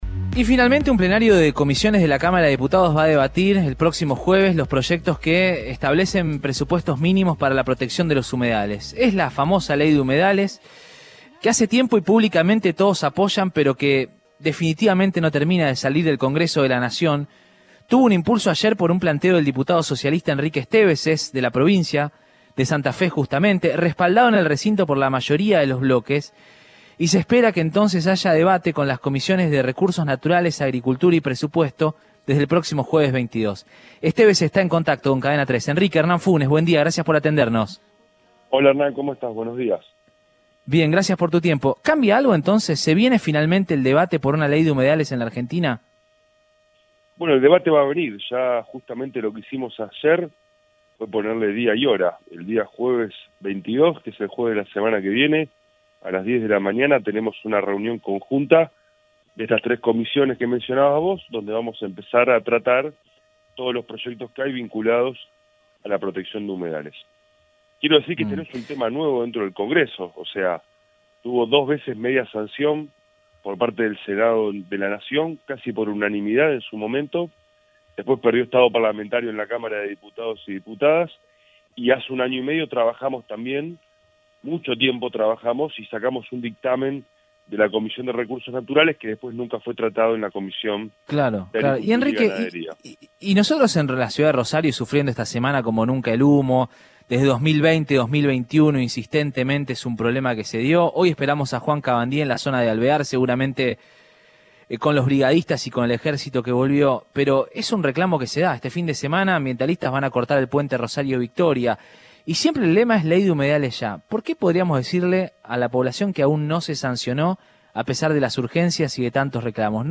Enrique Estévez, diputado santafesino del Partido Socialista, se refirió al debate de los proyectos de ley de humedales que tendrá lugar el próximo jueves.
Damos pautas para que no terminen de matar a nuestros humedales”, explicó Estévez en Radioinforme 3, por Cadena 3 Rosario.